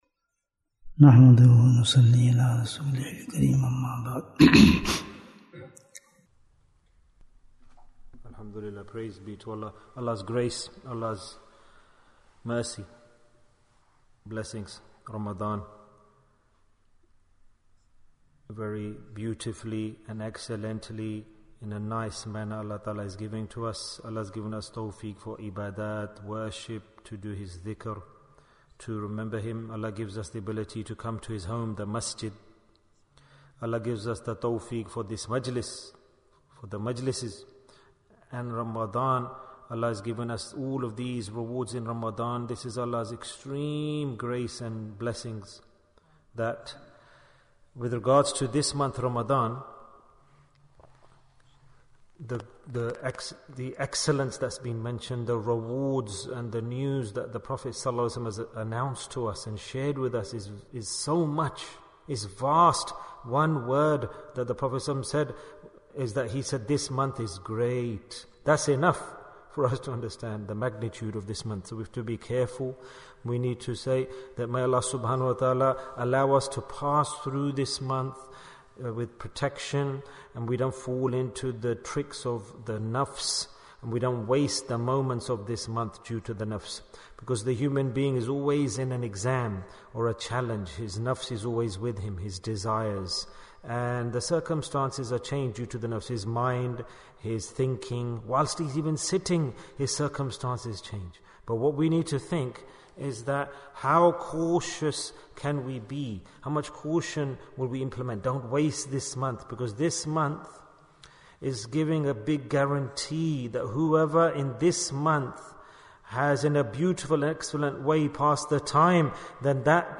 What is a Deed for the Second Asharah? Bayan, 49 minutes5th April, 2023